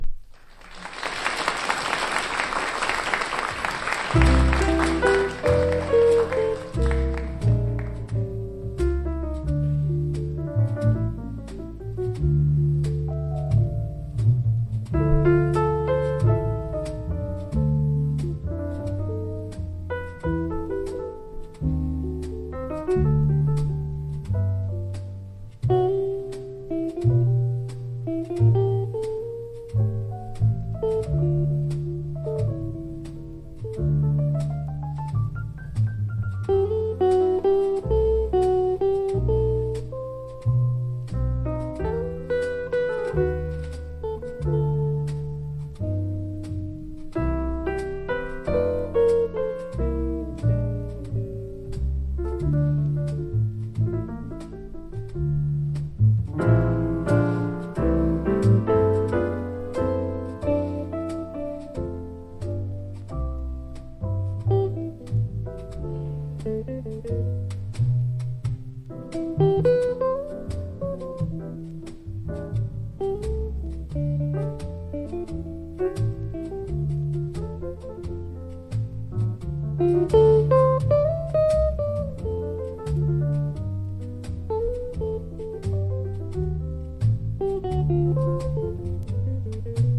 これまでの作品に比べてポップな側面を全面に出したアルバムです。